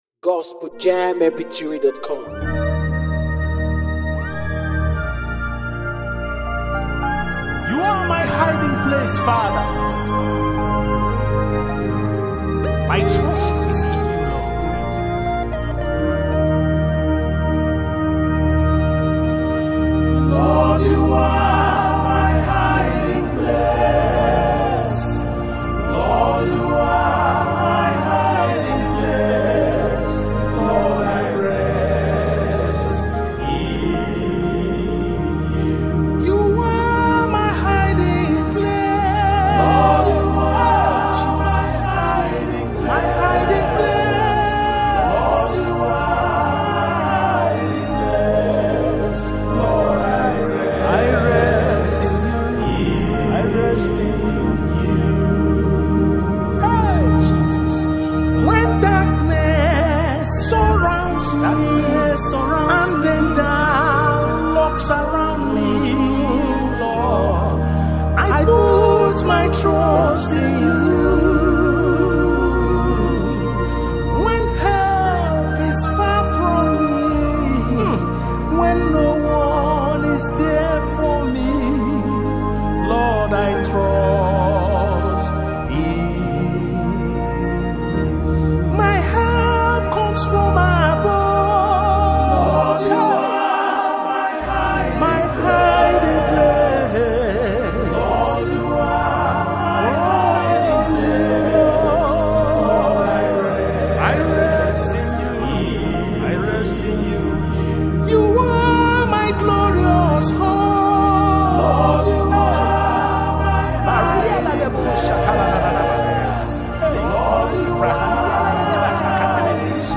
It’s a song of worship